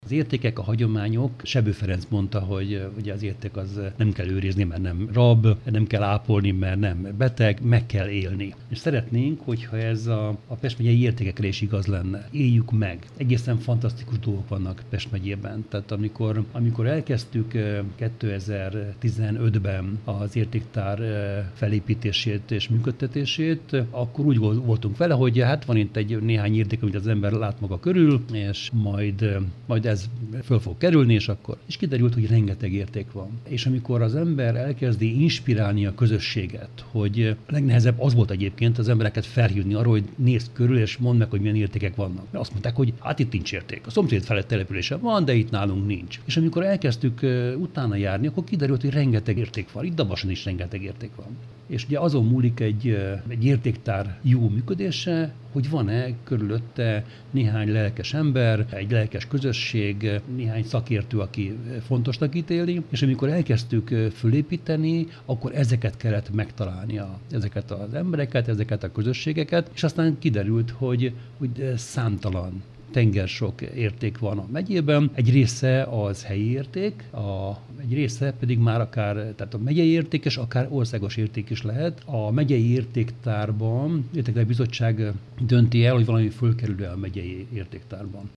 Nehéz volt rávezetni az embereket, hogy ismerjék fel a környezetük értékeit - ezt mondta Szabó István, a Pest vármegyei Közgyűlés elnöke.
A vármegyei értéktár több tucat értékkel rendelkezik. Szabó Istvánt hallják.